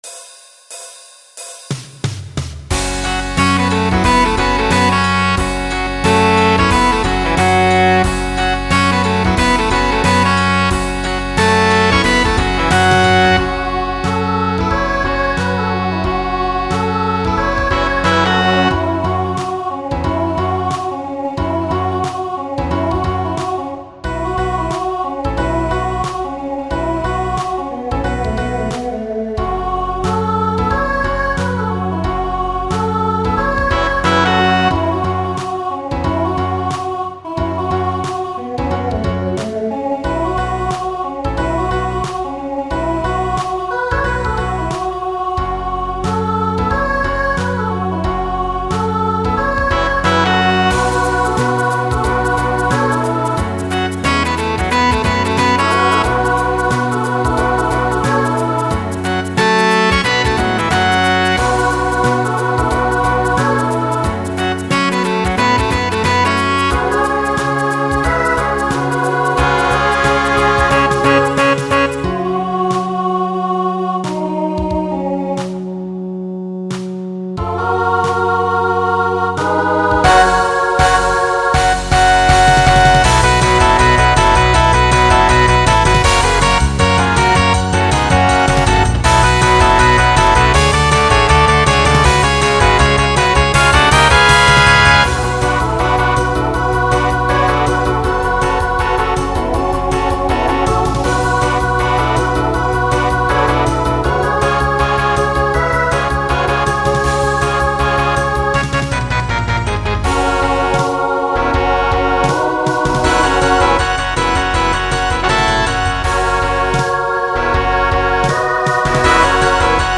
Genre Country Instrumental combo
Transition Voicing SSA